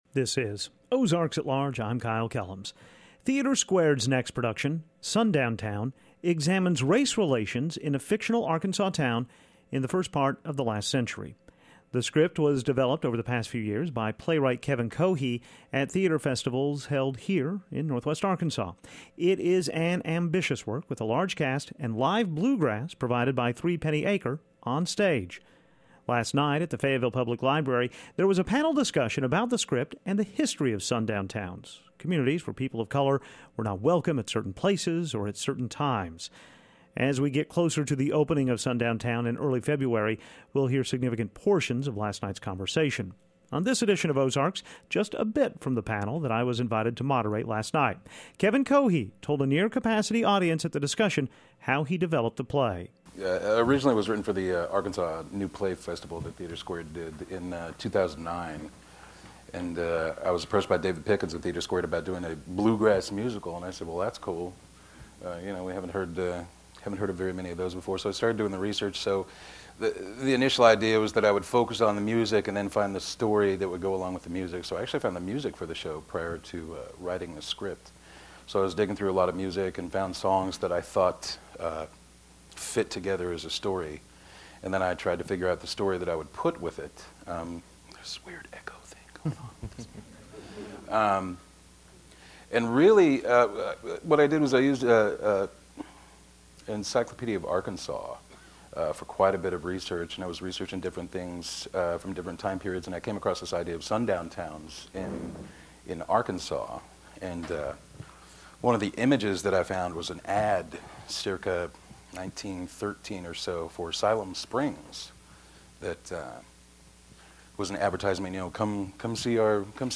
The script takes a bold look at race relations. We'll hear some of the discussion about the script held last night at the Fayetteville Public Library.